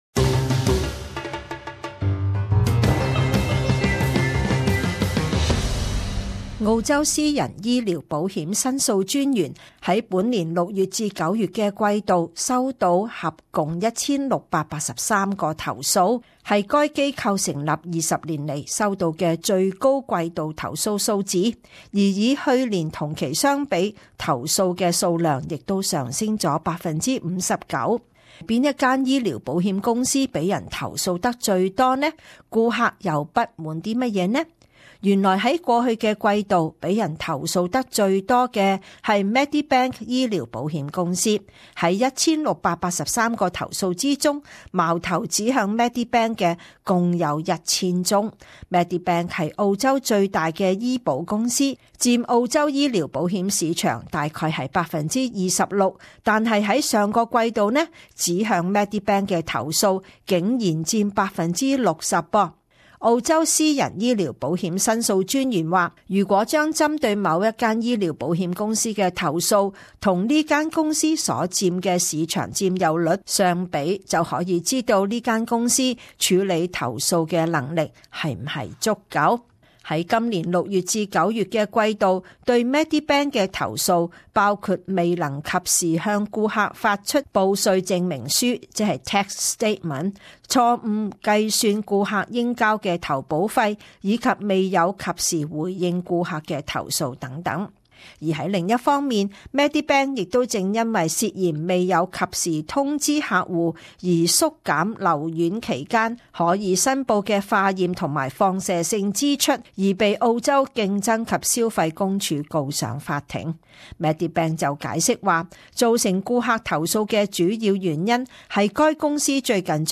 【时事报导】 医疗保险投诉创新高